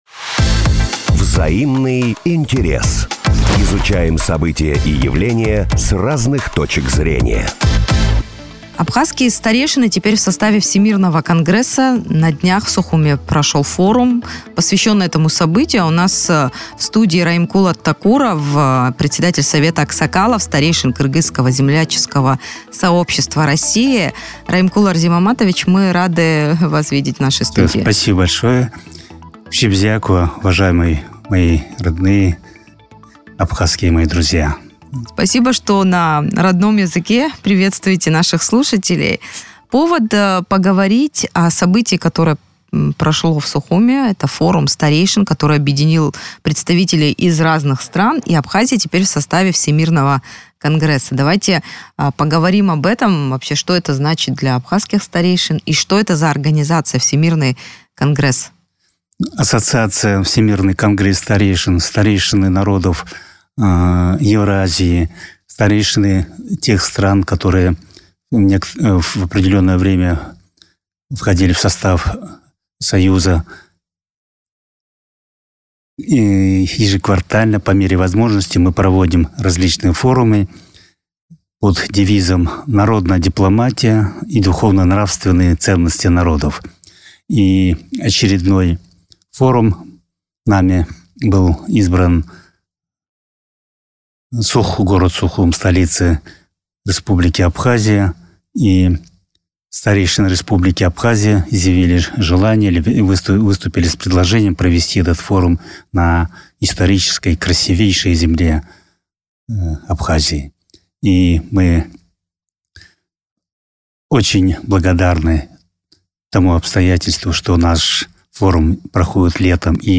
В интервью радио Sputnik